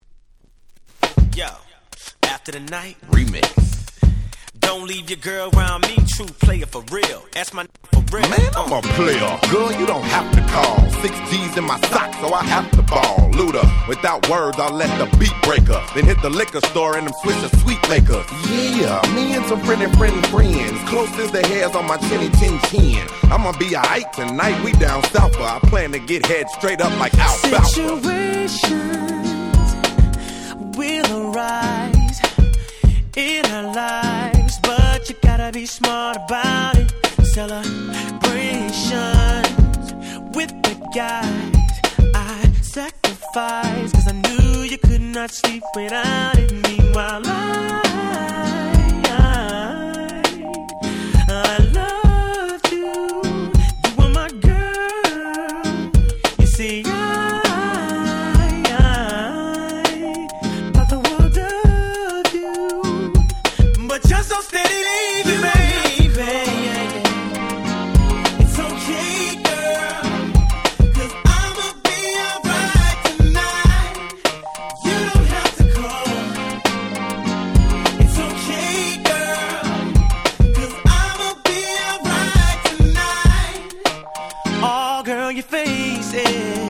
説明不要のフロアチューン！！